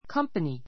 kʌ́mpəni